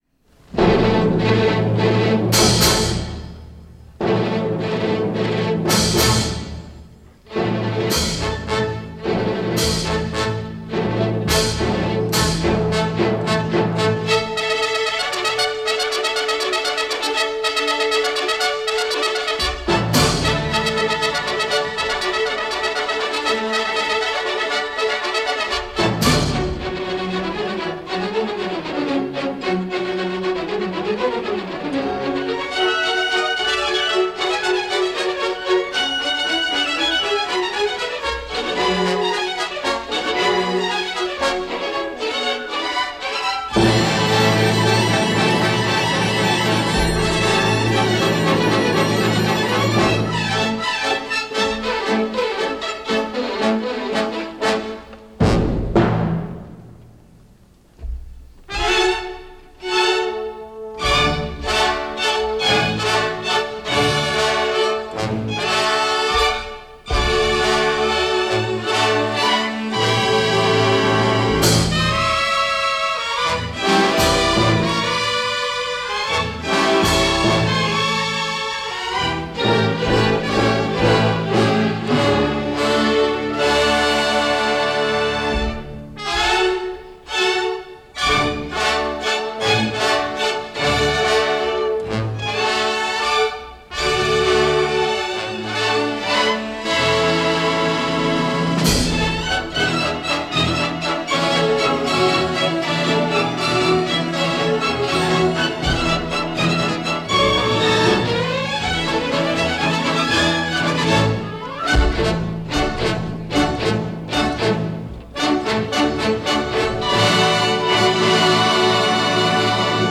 A brief stopover in Paris this evening for a portion of a concert, recorded May 20, 1958 with Orchestre National de la ORTF conducted by the legendary Pierre Dervaux.
Fete Polonaise by Emmanuel Chabrier – a composer who was as rough on pianos as he was on orchestras. This is certainly a showpiece – and in Dervaux’s deft hands and the orchestra’s deft skill – makes for an explosive performance.
For the time being, click on the link and press Play and dive in for the next 8 minutes with some very high voltage and downright joyous music.